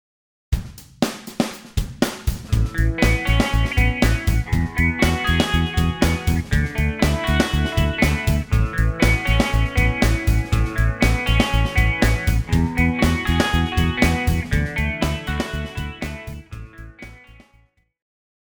So, if your chord progression is A E D A, you will play Aadd9, Eadd9, Dadd9, A add 9 instead. (